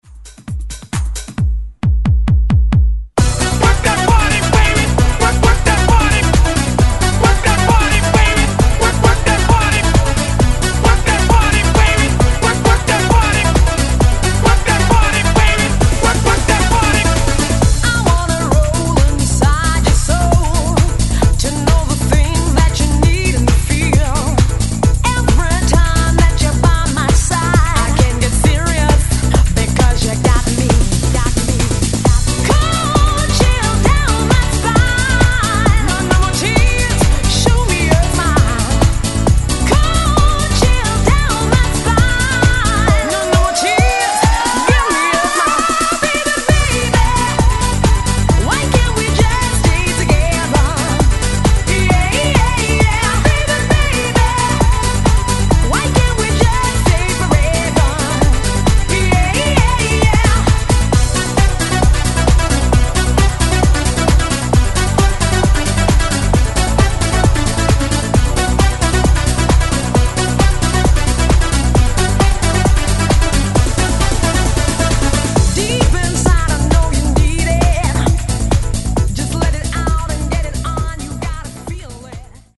Genres: LATIN , REGGAETON Version: Clean BPM: 90 Time